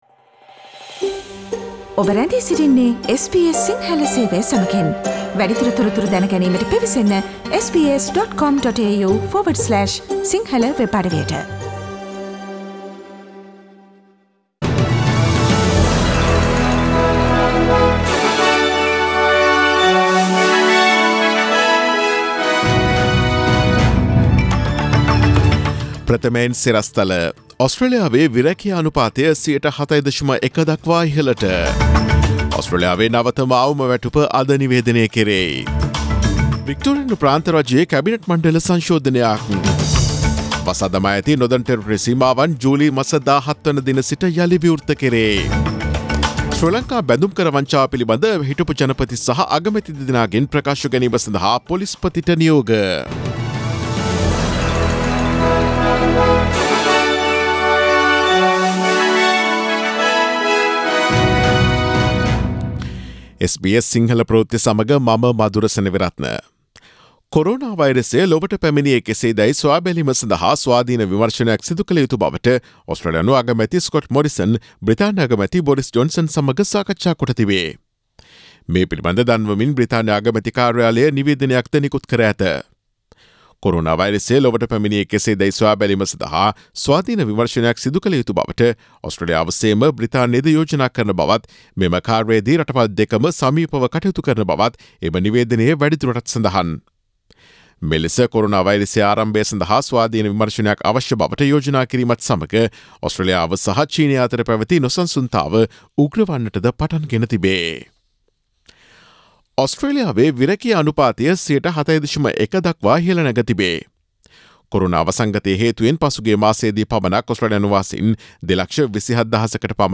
Daily News bulletin of SBS Sinhala Service: Friday 19 June 2020